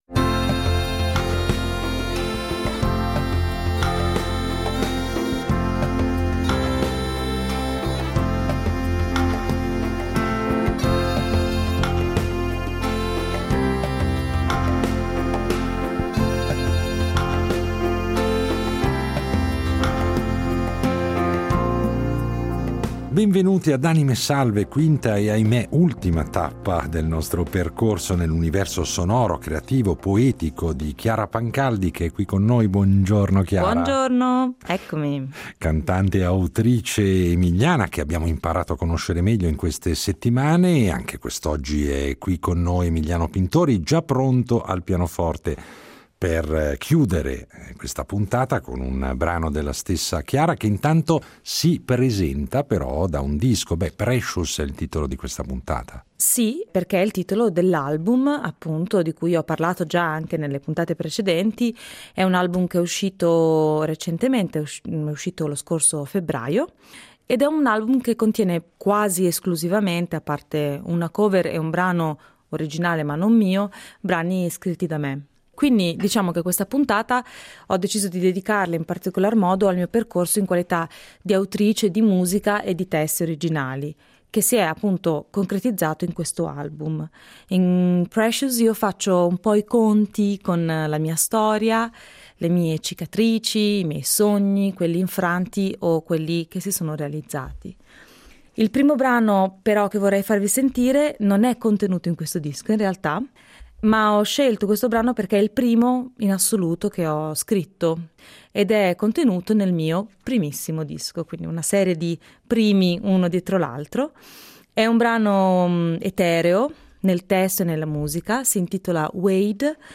in versioni più intime